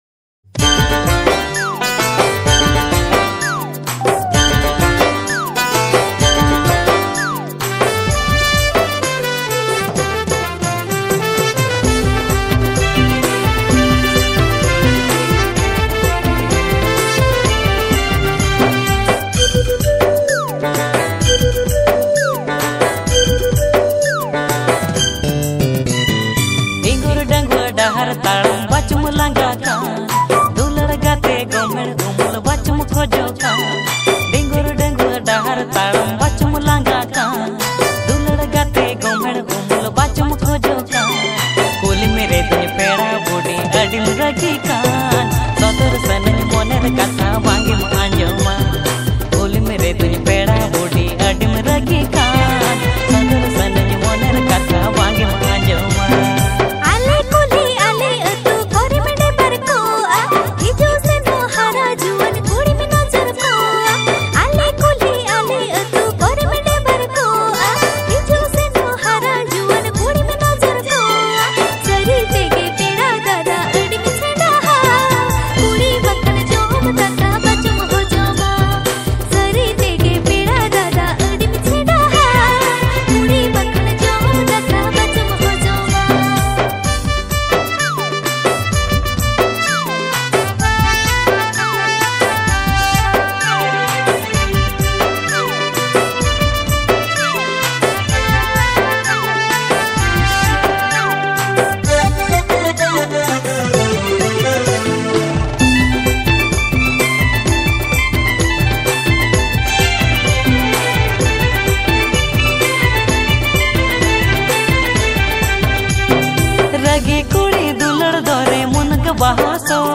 • Male Artist
• Female Artist